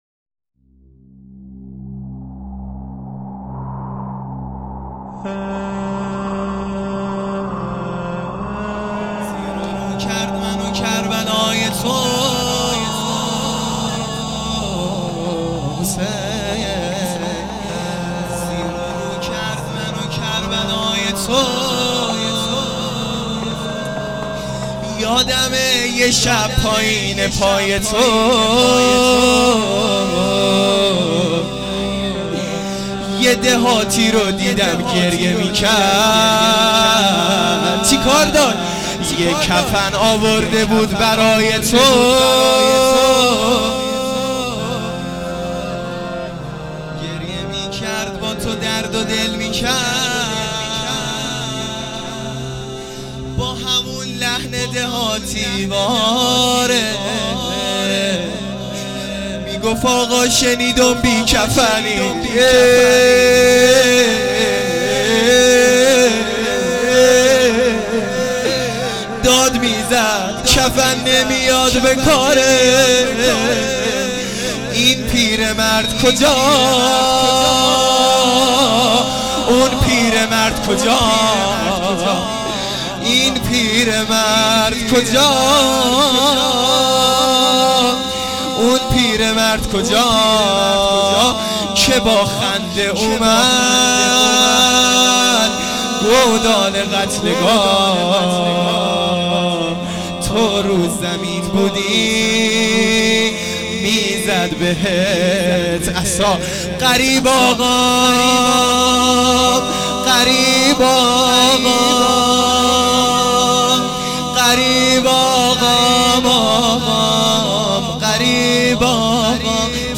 روضه پایانی | شنیدم بی کفنی